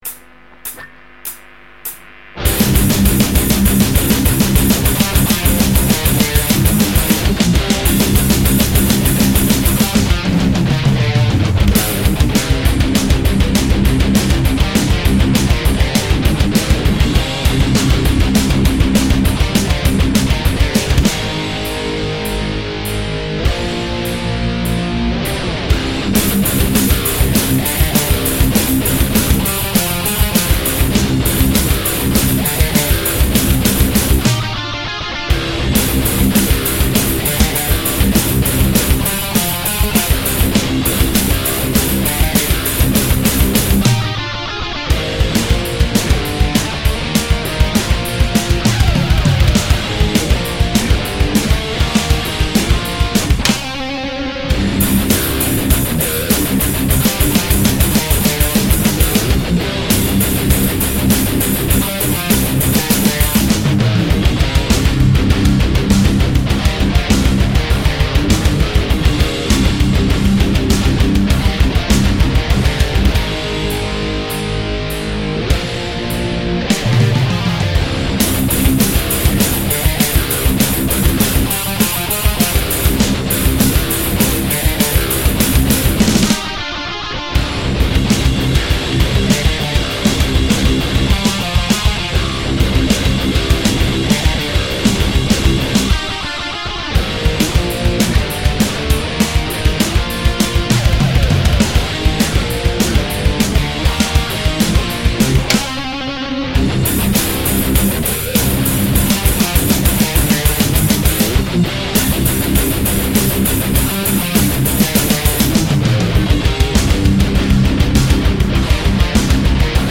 Metalcore, Heavy Metal